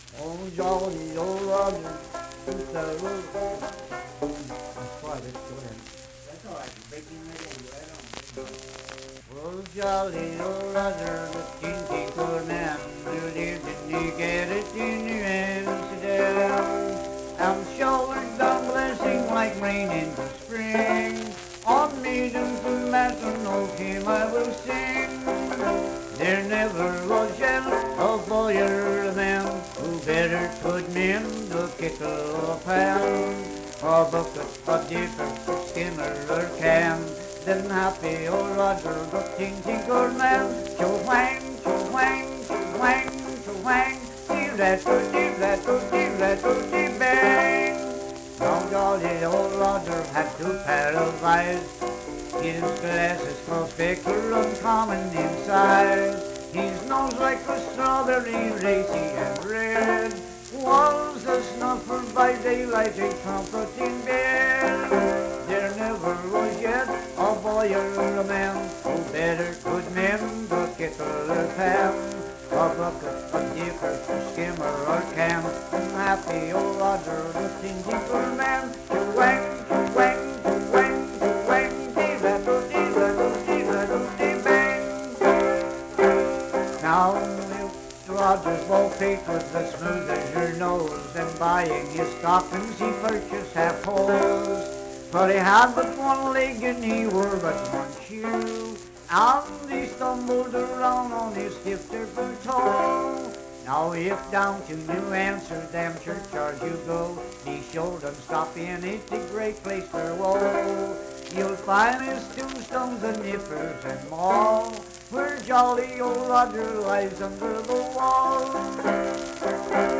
Banjo accompanied vocal music performance
Verse-refrain 4(4) & R(6).
Banjo, Voice (sung)